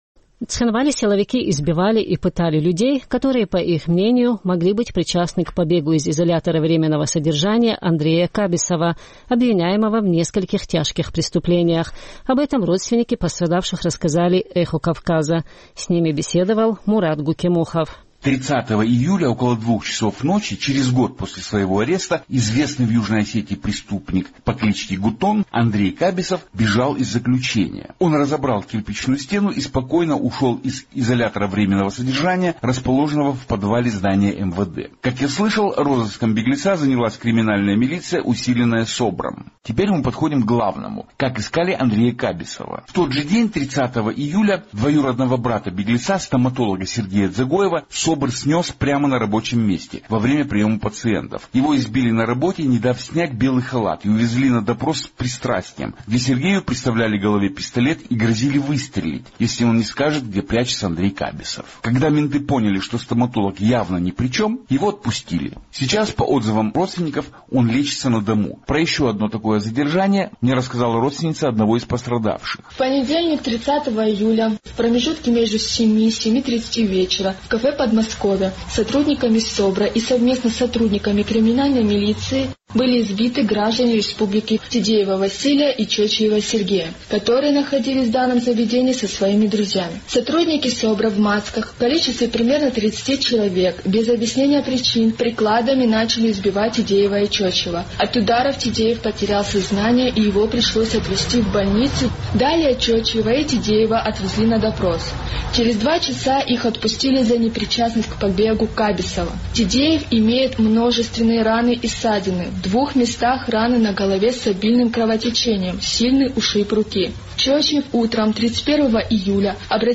Вы, наверное, заметили, что девушка, представленная в этом сюжете как родственница одного из пострадавших, читала текст.